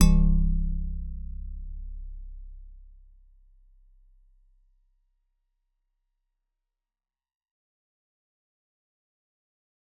G_Musicbox-C1-f.wav